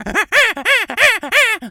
monkey_chatter_angry_01.wav